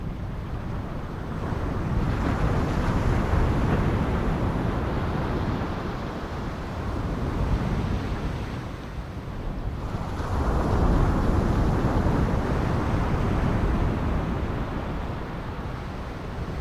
beach.ogg